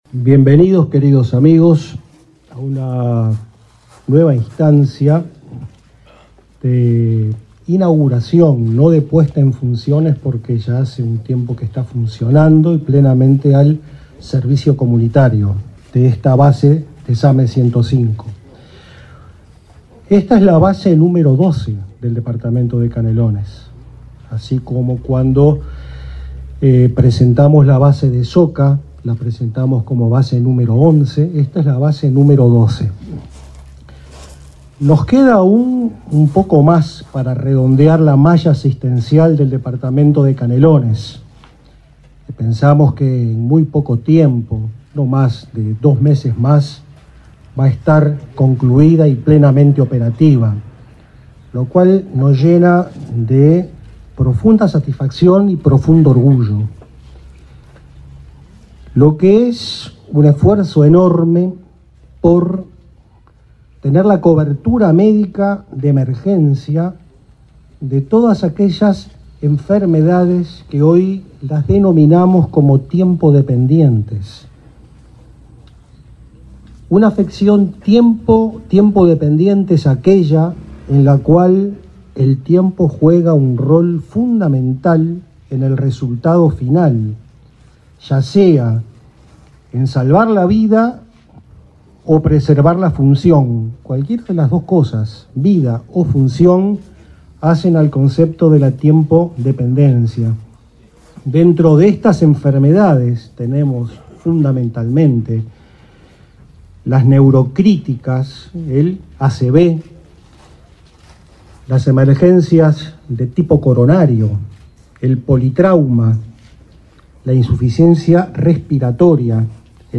Palabras de autoridades en acto de ASSE en Canelones
El director del Sistema de Atención Médica de Emergencia (SAME), José Antonio Rodríguez, y el presidente de la Administración de los Servicios de